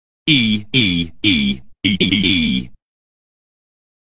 ee sports sound button getting viral on social media and the internet Here is the free Sound effect for ee sports that you can download and make hilarious
ea-sports-meme-eeee-1.mp3